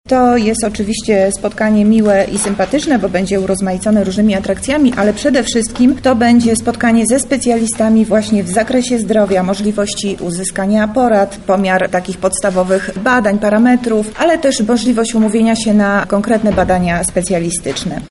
– mówi Monika Lipińska, zastępca Prezydenta Miasta Lublin.